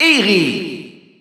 Announcer pronouncing Iggy in French.
Category:Bowser Jr. (SSBU) Category:Announcer calls (SSBU) You cannot overwrite this file.
Iggy_Spanish_Announcer_SSBU.wav